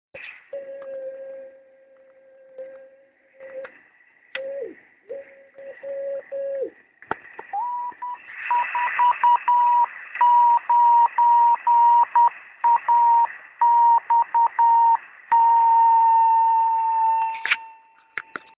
CW
Beacon swl report